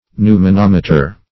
Search Result for " pneumonometer" : The Collaborative International Dictionary of English v.0.48: Pneumonometer \Pneu`mo*nom"e*ter\, n. [See Pneumo- , and -meter .]
pneumonometer.mp3